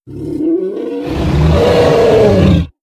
Mutant_Jump.wav